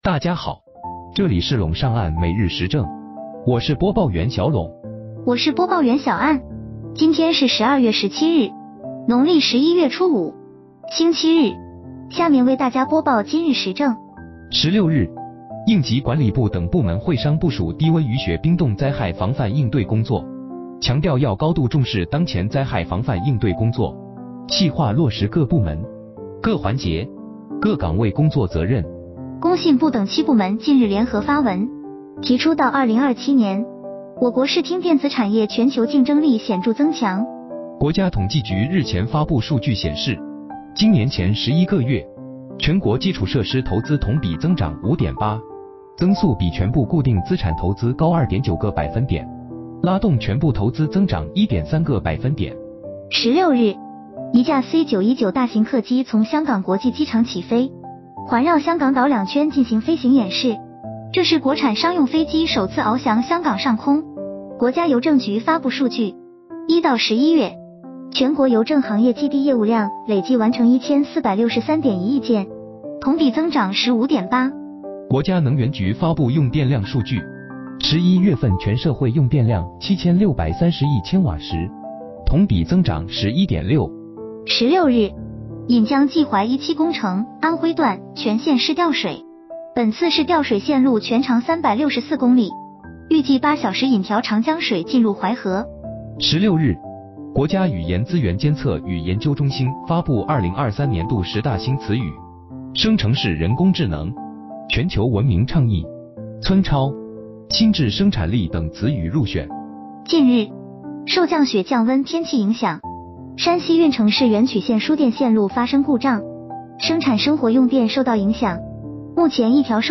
▼今日时政语音版▼